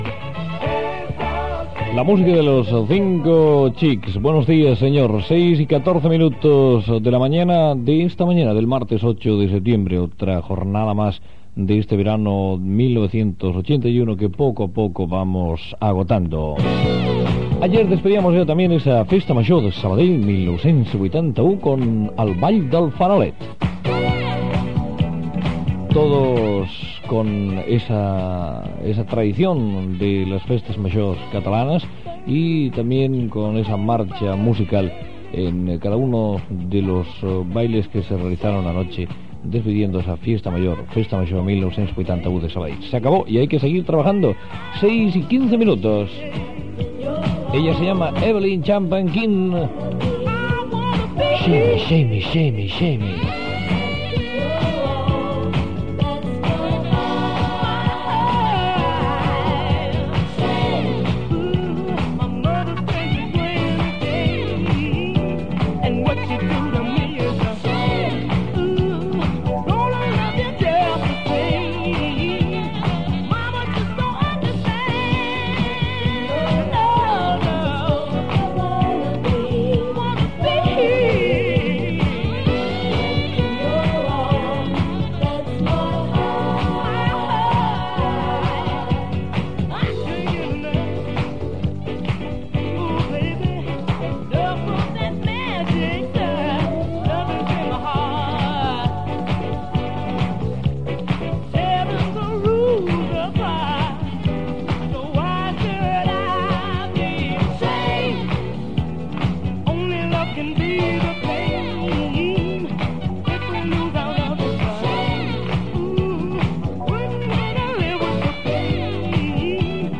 Record a la Festa Major de Sabadell. Presentació de temes musicals amb esment a l'hora i la temperatura i indicatiu de l'emissora.
Musical